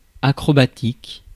Ääntäminen
Ääntäminen France: IPA: [a.kʁɔ.ba.tik] Haettu sana löytyi näillä lähdekielillä: ranska Käännös Adjektiivit 1. acrobatic Suku: f .